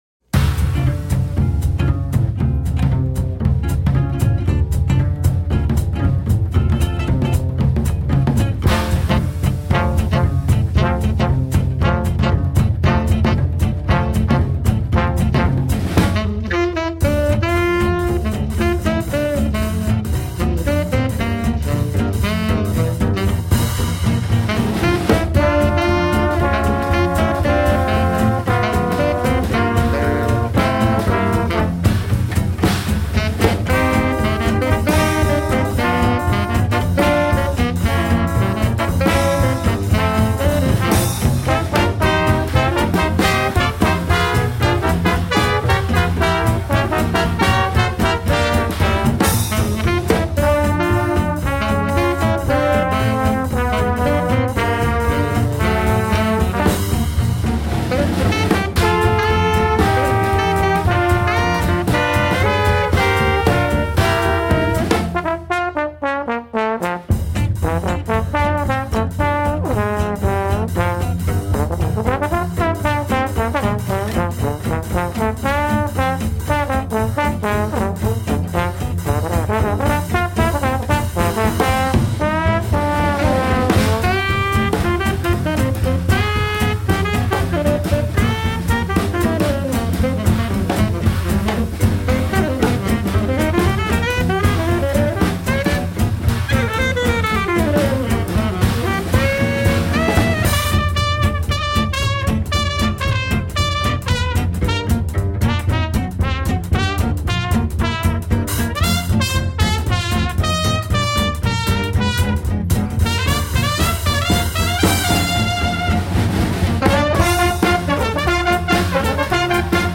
Vintage swing in the 21st century.